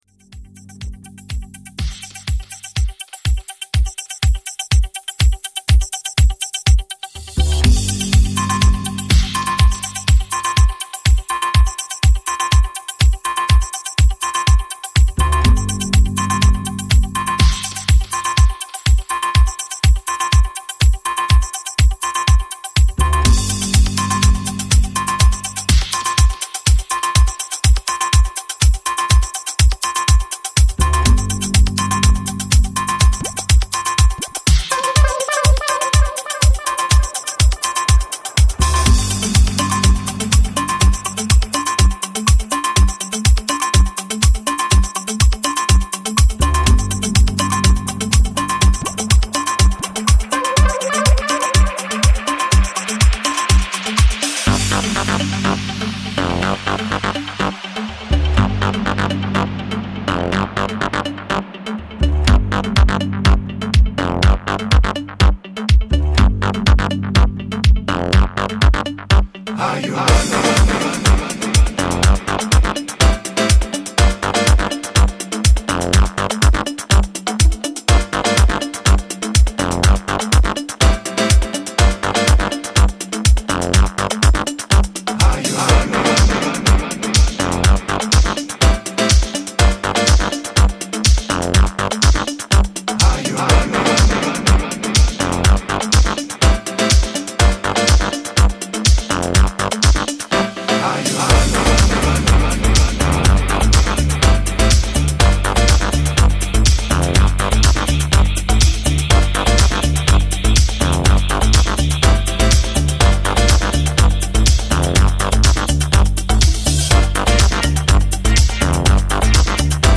Vocals and Bass
super cool 90s disco remix